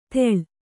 ♪ tevḷ